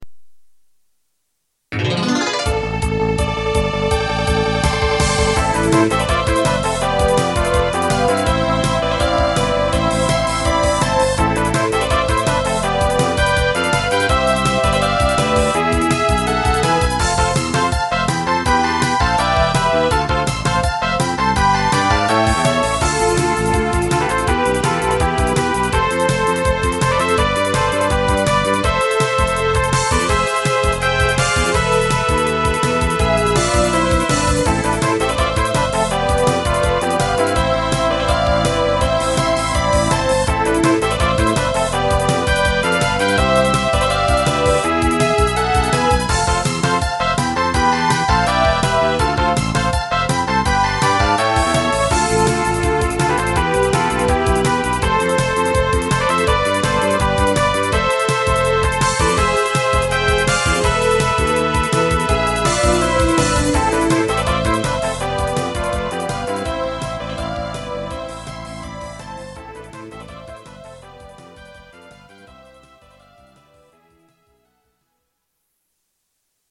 管理人が作ったMIDI集です
ストリングスとトランペットが熱い。
いかにもスーファミっぽいメロディーだけど、そこがいい。